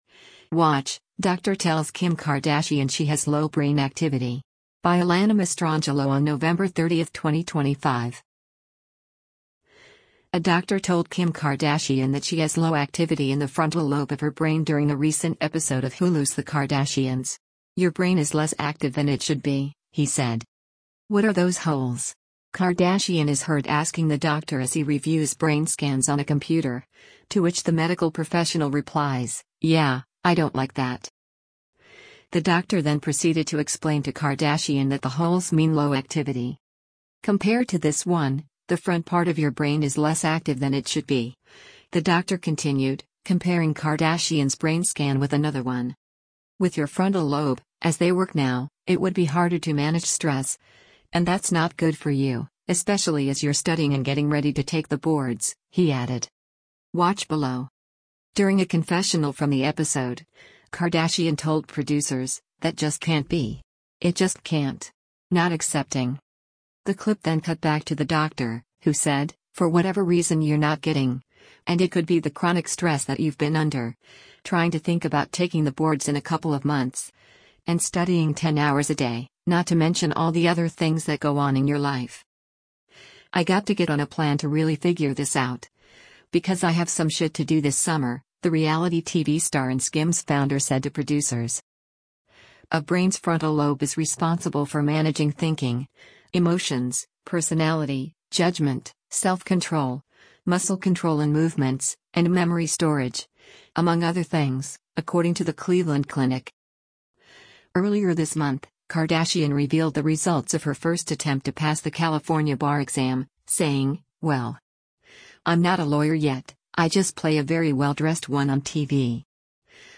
“What are those holes?” Kardashian is heard asking the doctor as he reviews brain scans on a computer, to which the medical professional replies, “Yeah, I don’t like that.”